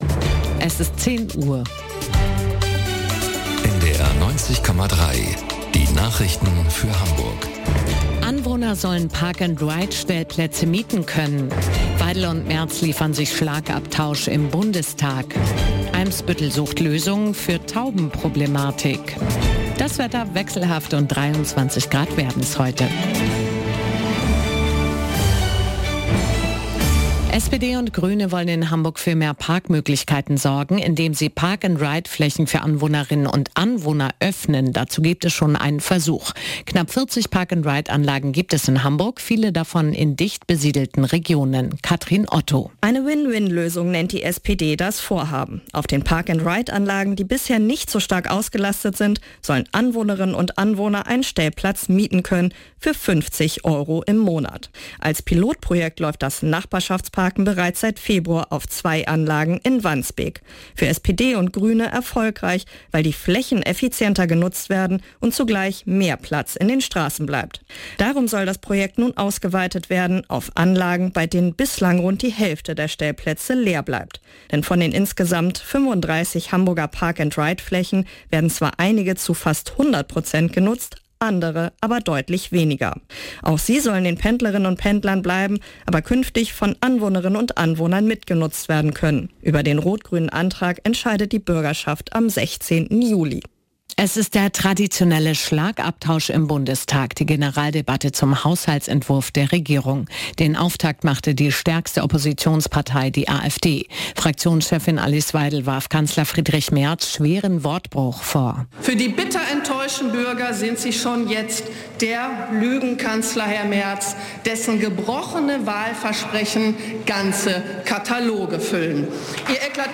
Nachrichten - 09.07.2025